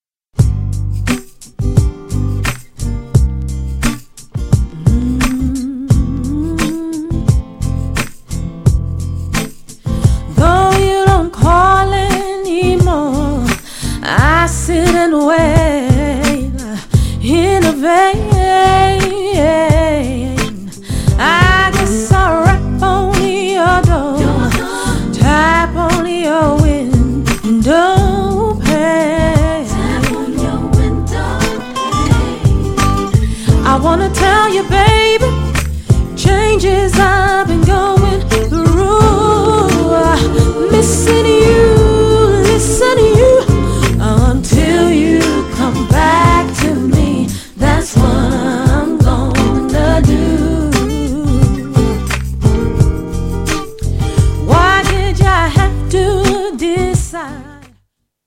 GENRE R&B
BPM 96〜100BPM